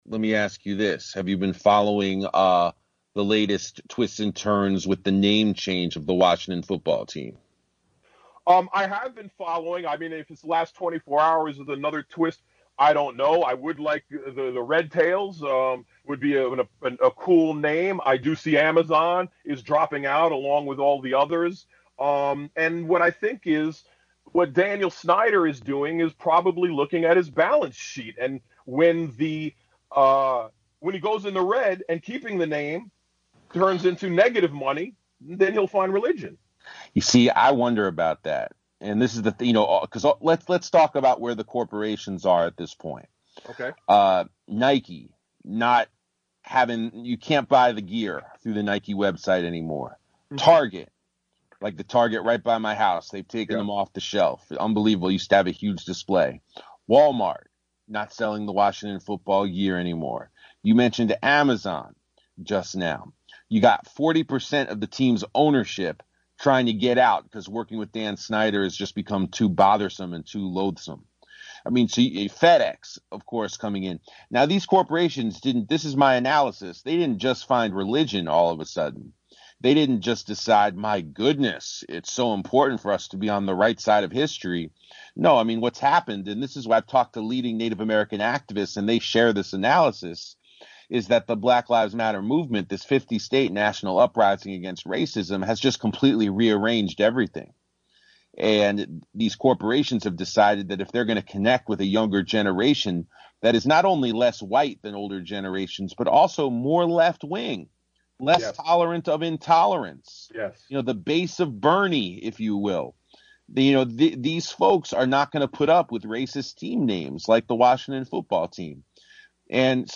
A certain Washington, DC Football team finally faces a name change after pressure over anti-native american racism. A discussion of the R-Word on The Collision: Sports and Politics, from Pacifica Radio WPFW.